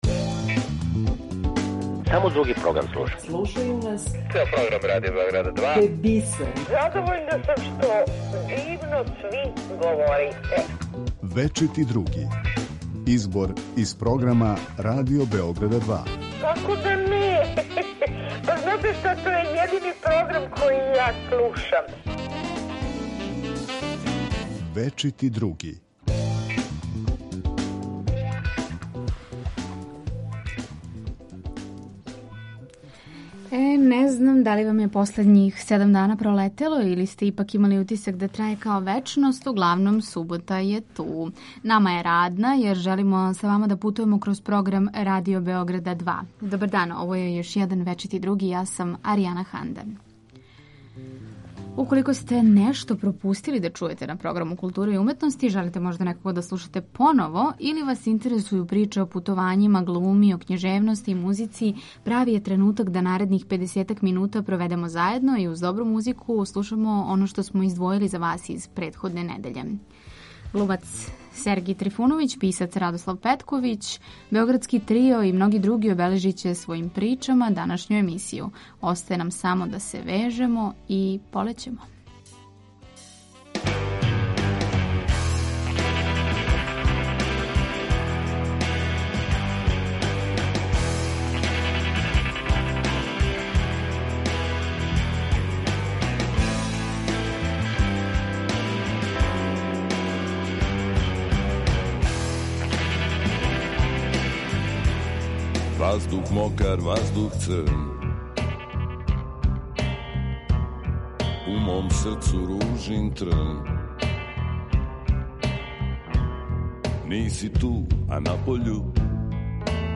Слушаћемо глумца Сергеја Трифуновића.
Писац Радослав Петковић говориће, између осталог, о разлици у писању некад и сад.